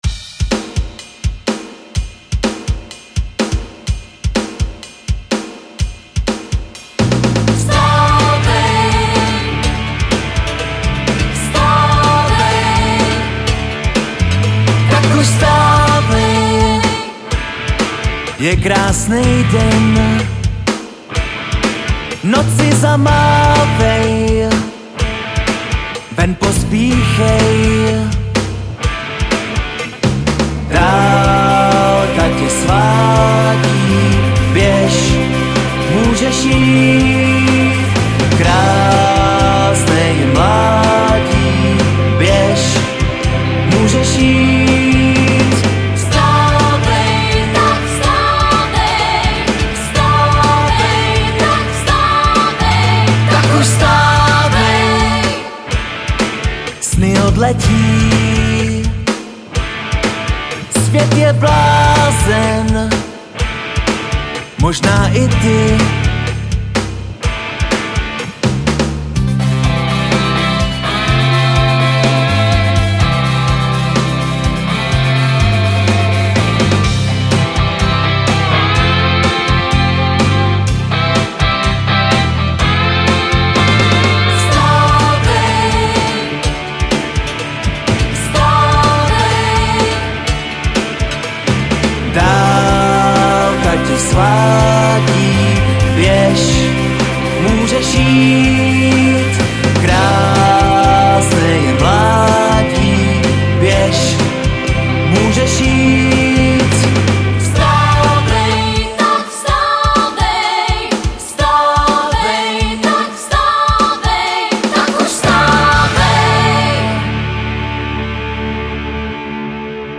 Žánr: Pop
veselý nekomplikovaný pop rock